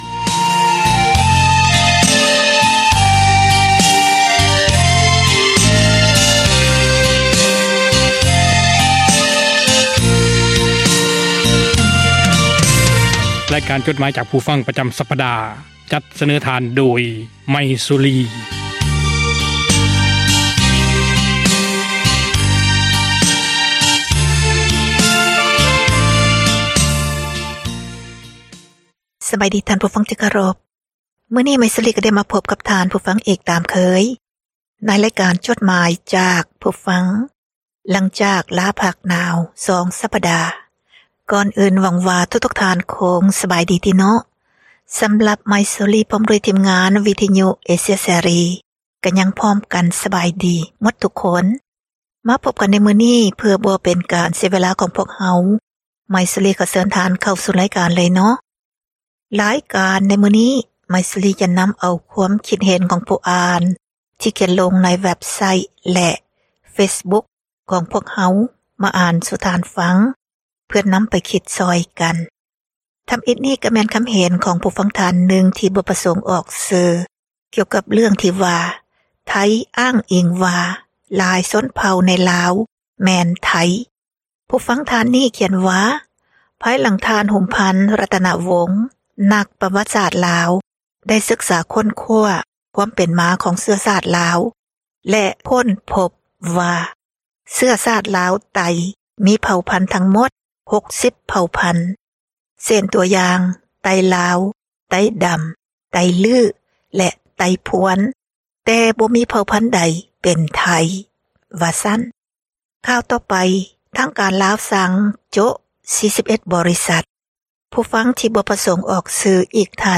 ໝາຍເຫດ: ຄວາມຄິດເຫັນ ຂອງທ່ານຜູ່ອ່ານ ທີ່ສະແດງອອກ ໃນເວັບໄຊທ໌ ແລະ ເຟສບຸກຄ໌ ຂອງວິທຍຸ ເອເຊັຽ ເສຣີ ນັ້ນ, ພວກເຮົາ ທິມງານ ວິທຍຸ ເອເຊັຽ ເສຣີ ໃຫ້ຄວາມສຳຄັນ ແລະ ຂອບໃຈ ນຳທຸກໆຖ້ອຍຄຳ, ແລະ ມີໜ້າທີ່ ນຳມາອ່ານໃຫ້ທ່ານ ໄດ້ຮັບຟັງກັນ ແລະ ບໍ່ໄດ້ເສກສັນປັ້ນແຕ່ງໃດໆ, ມີພຽງແຕ່ ປ່ຽນຄຳສັພ ທີ່ບໍ່ສຸພາບ ໃຫ້ເບົາລົງ ເທົ່ານັ້ນ. ດັ່ງນັ້ນ ຂໍໃຫ້ທ່ານຜູ່ຟັງ ຈົ່ງຕັດສິນໃຈເອົາເອງ ວ່າ ຄວາມຄຶດຄວາມເຫັນນັ້ນ ເປັນໜ້າເຊື່ອຖື ແລະ ຄວາມຈິງ ຫຼາຍໜ້ອຍປານໃດ.